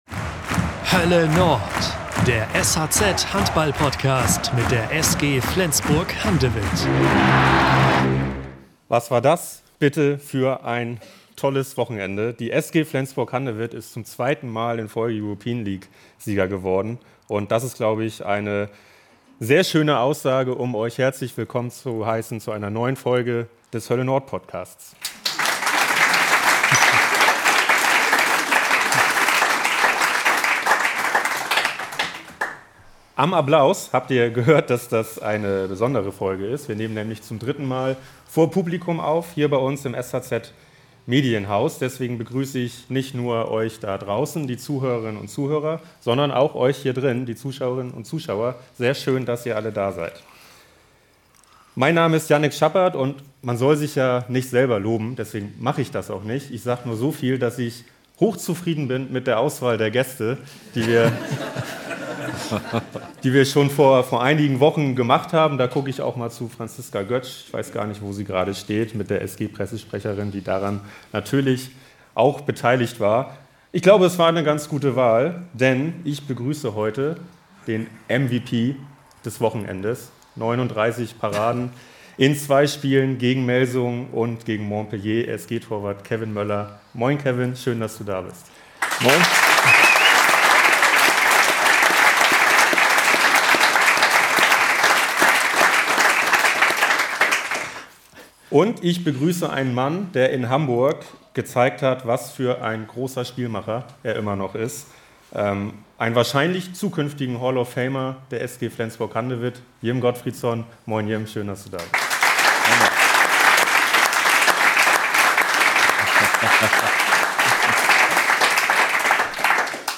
Keine 48 Stunden nach einer langen Party-Nacht tauchen Jim Gottfridsson und „MVP“ Kevin Möller im „Hölle Nord“-Podcast, diesmal vor Publikum aufgenommen, noch einmal ein in die Erlebniss...
Die beiden langjährigen SG-Spieler erzählen außerdem, warum sie eine enge Freundschaft verbindet und vor allem Gottfridsson wird emotional, als es um das nahende Ende seiner Zeit in Flensburg geht.